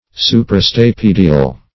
Search Result for " suprastapedial" : The Collaborative International Dictionary of English v.0.48: Suprastapedial \Su`pra*sta*pe"di*al\, a. (Anat.)